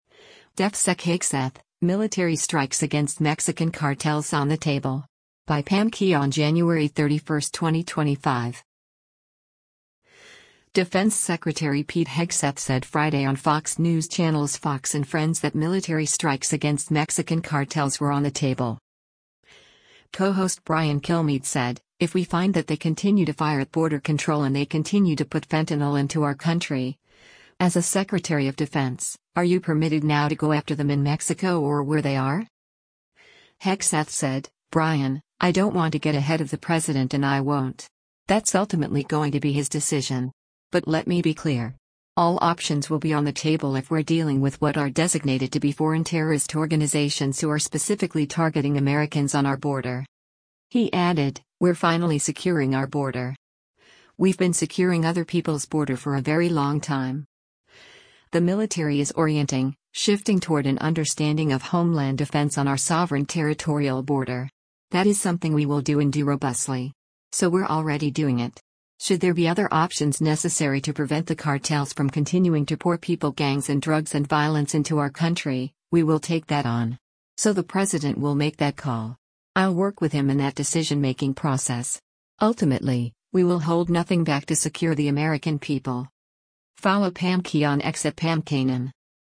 Defense Secretary Pete Hegseth said Friday on Fox News Channel’s “Fox & Friends” that military strikes against Mexican cartels were “on the table.”